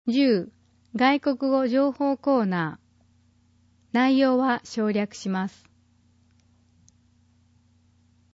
広報とうごう音訳版（2019年10月号）